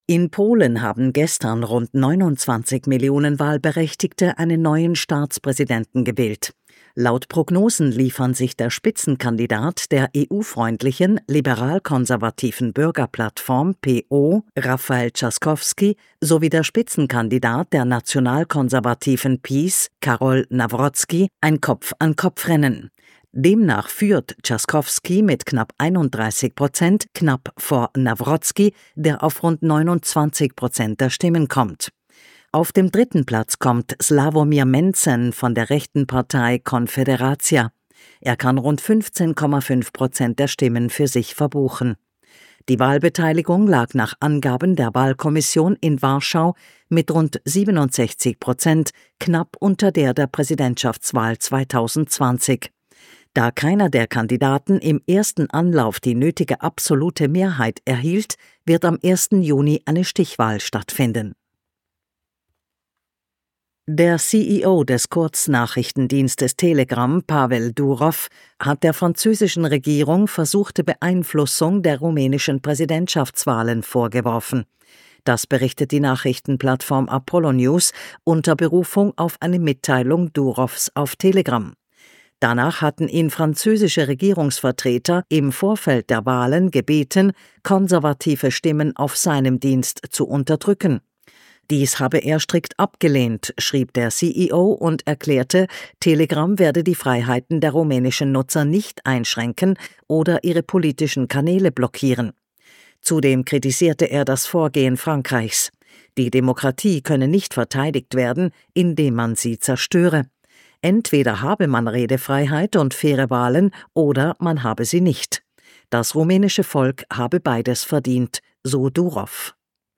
Addendum: Jetzt auch in den Nachrichten des KONTRAFUNKS: